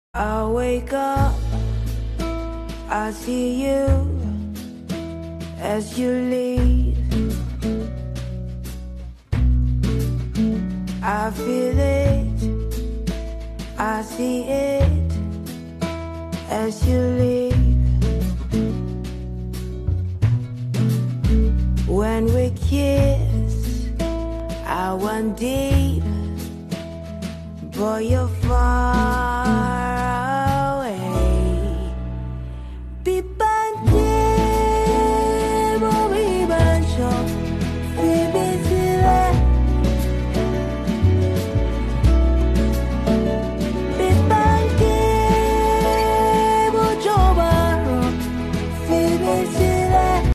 Hmmmm… sound effects free download